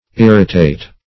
Irritate \Ir"ri*tate\, a.